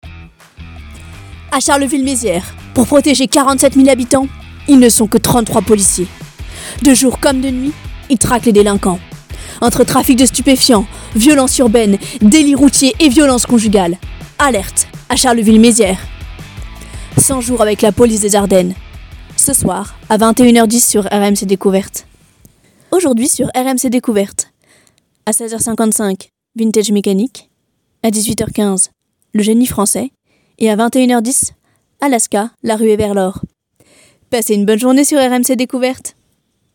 Voix off
maquette bande annonce